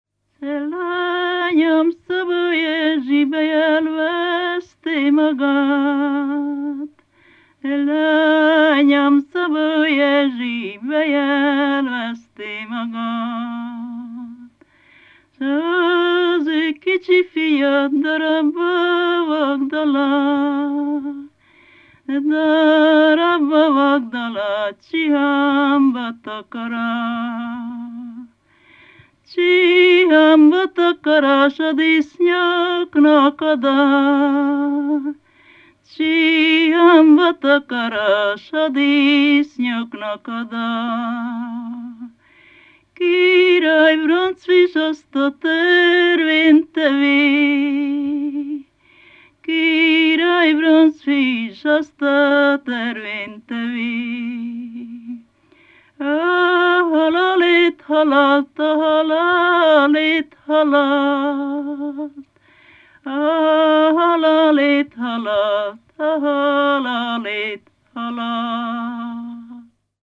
ének
ballada
Lészped
Moldva (Moldva és Bukovina)